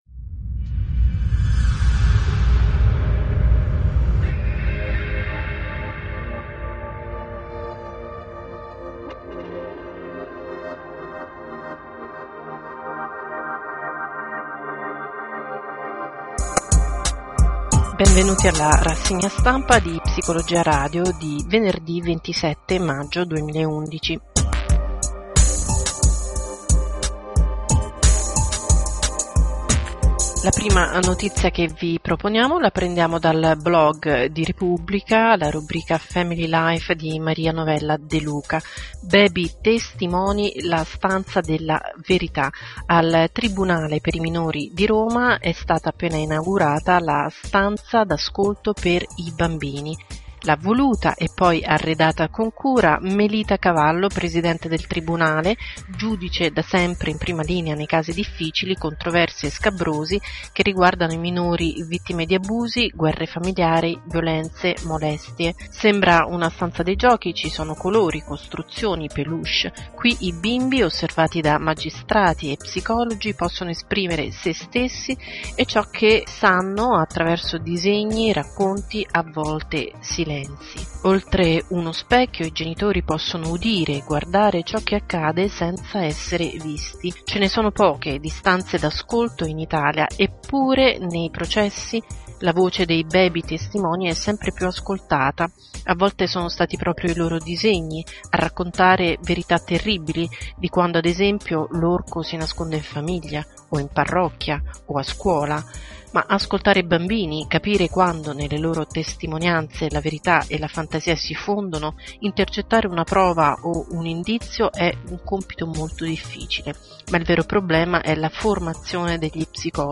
RASSEGNA STAMPA 27 MAGGIO 2011
Musica: Fairytale di Kämmerer